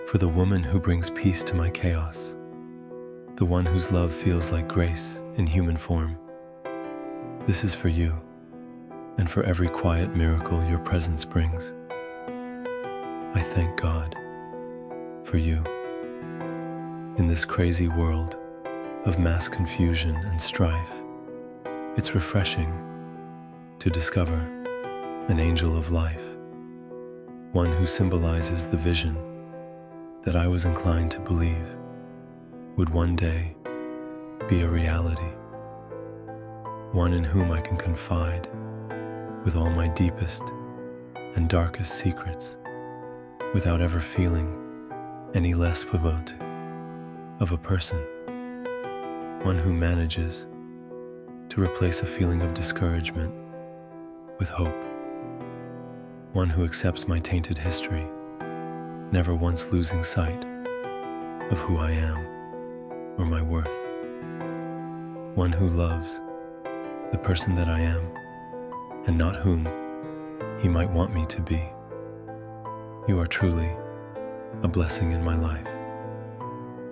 I-Thank-God-For-You-–-Love-Poem-Spoken-Word-mp3.mp3